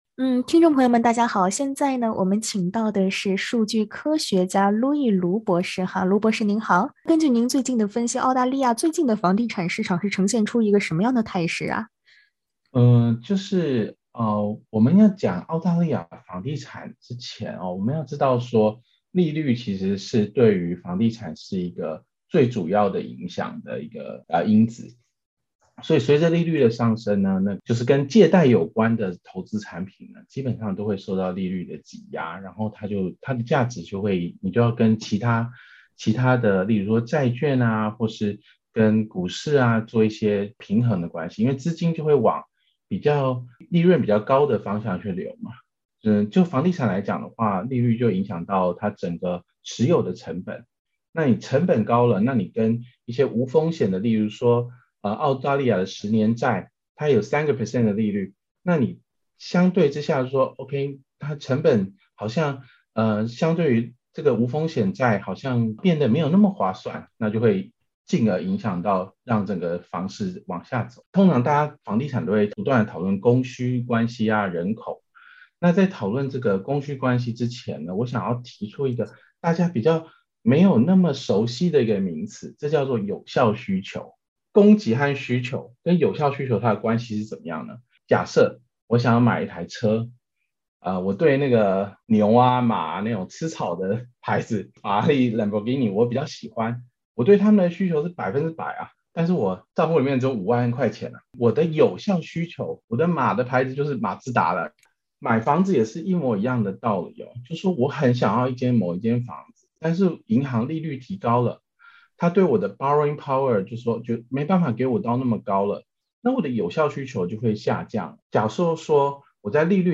現金利率上漲是如何影響房地產市場的？（點擊上圖收聽寀訪）